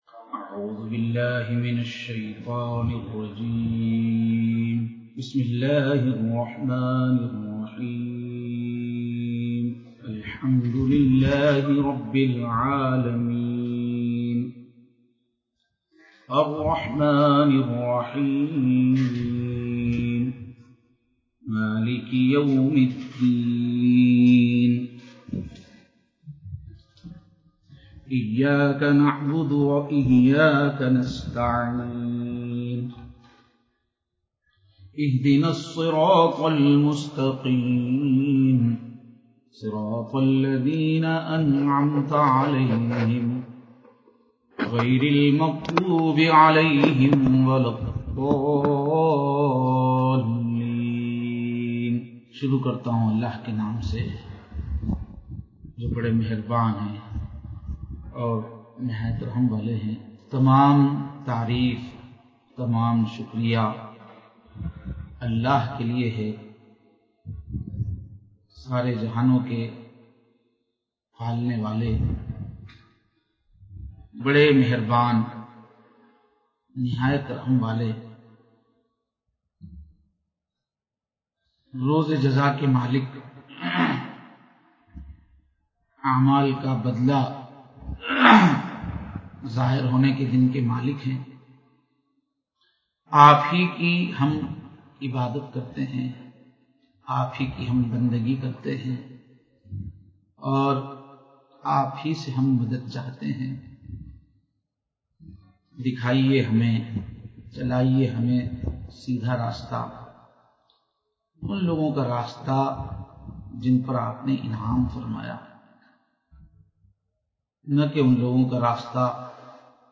Live Online Bayan